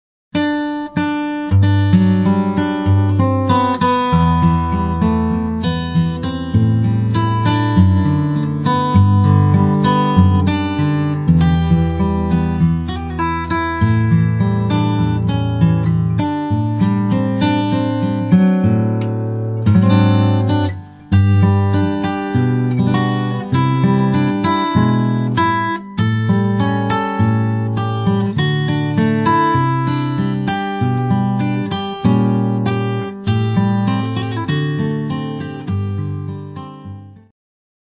TAB & Standard Notation A beautiful hallmark hymn